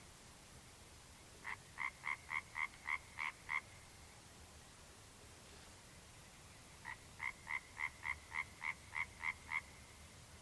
Dziesma mazliet atgādina pīļu pēkšķēšanu vai tarkšķēšanu, ar ko acīmredzot saistīts tās senais nosaukums – parkšķis. Personīgi man dziesma atgādina monotonu, ātru “zāģēšanu”. Labos laika apstākļos dziesma ir tālu dzirdama, pat līdz 1,5 km attālumam, īpaši, ja tas ir liels kokvaržu tēviņu koris.